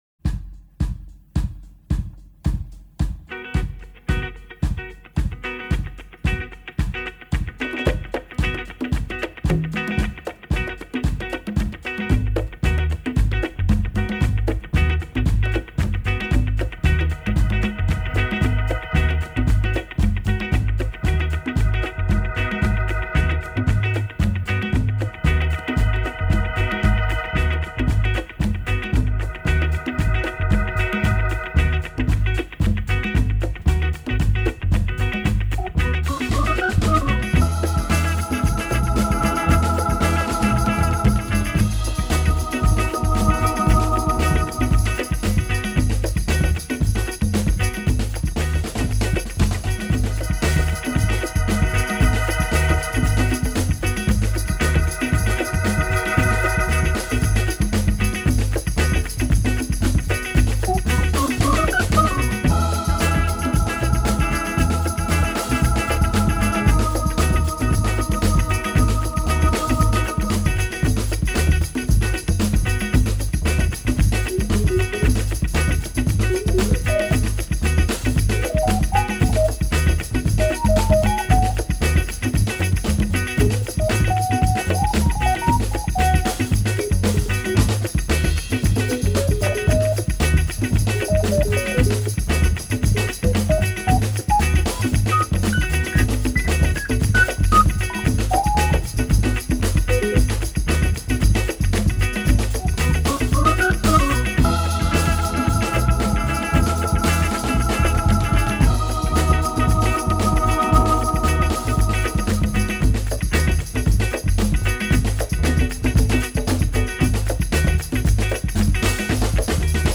that bass playing and arranging doesn’t come much groovier.